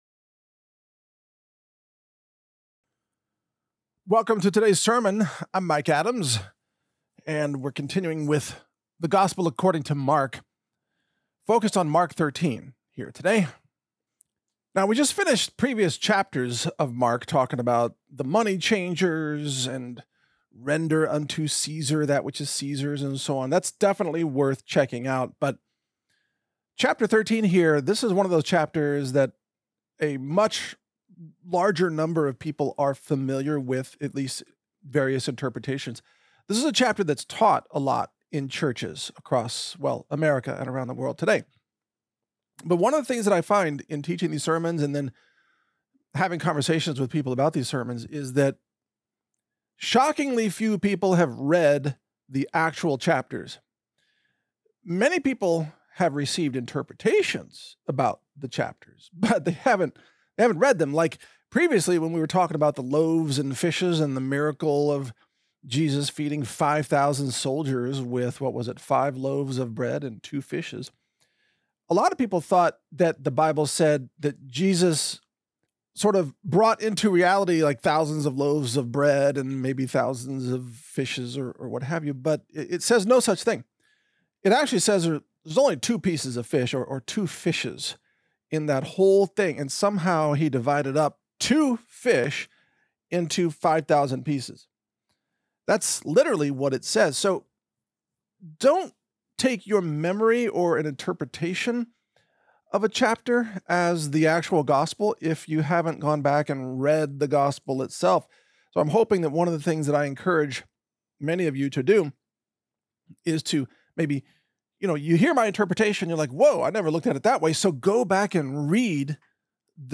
Mike Adams Sermon #031 - Mark 13 - Christ warns humanity about FIFTH GENERATION WARFARE during the Tribulation - Natural News Radio